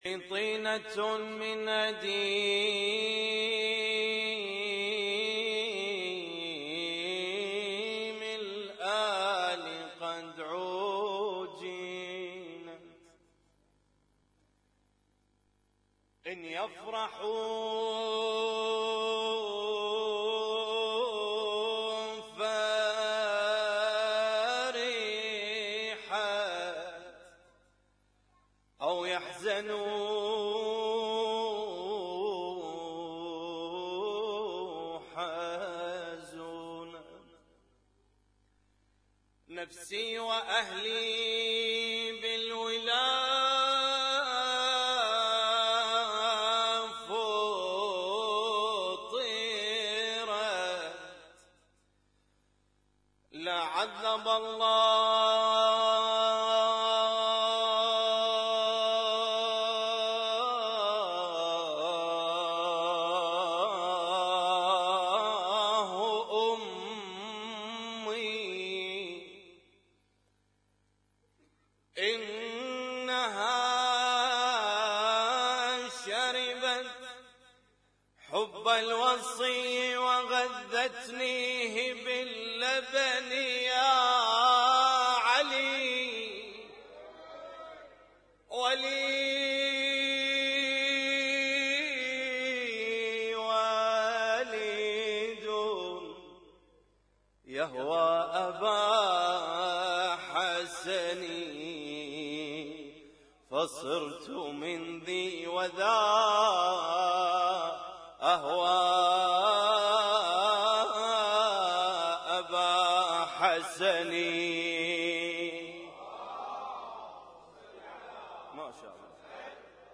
Husainyt Alnoor Rumaithiya Kuwait
ليلة 11من ذو القعدة 1438 - مولد الامام الرضا عليه السلام
اسم التصنيف: المـكتبة الصــوتيه >> المواليد >> المواليد 1438